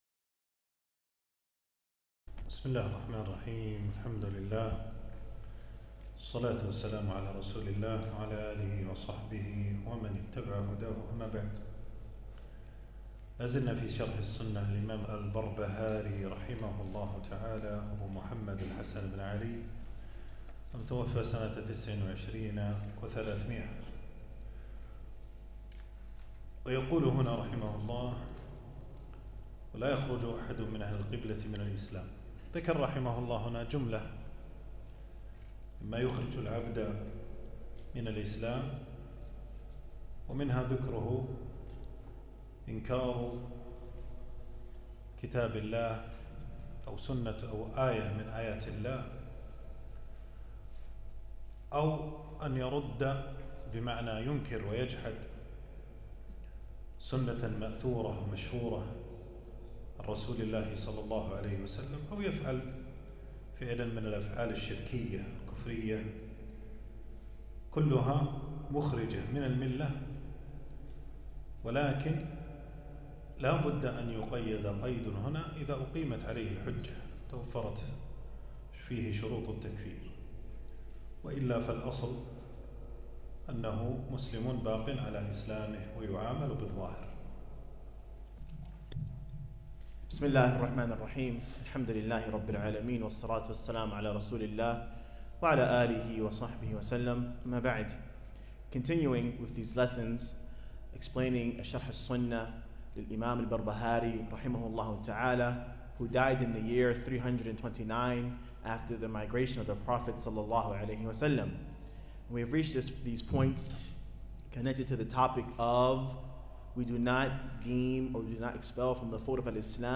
المكان: درس ألقاه يوم السبت 18 جمادى الأول 1447هـ في مسجد السعيدي.